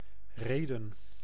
Rheden (Dutch pronunciation: [ˈreːdə(n)]
Nl-Rheden.ogg.mp3